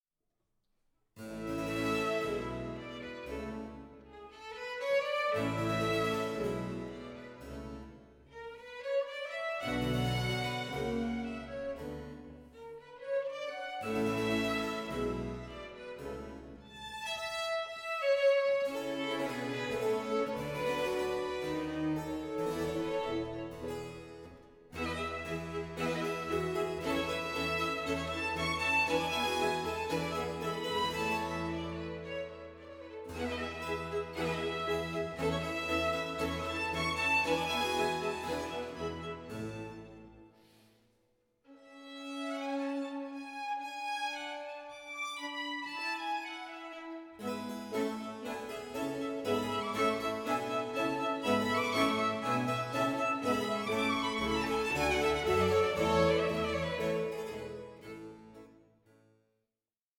Aria of Mandane